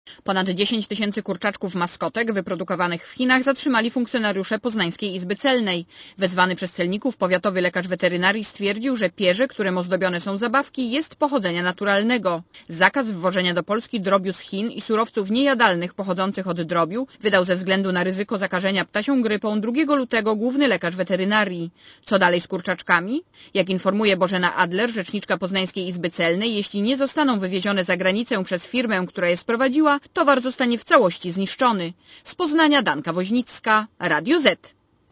Posłuchaj relacji reporterki Radia Zet (140 KB)